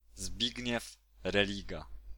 Zbigniew Eugeniusz Religa (Polish: [ˈzbiɡɲɛf rɛˈliɡa]
Pl-Zbigniew_Religa.ogg.mp3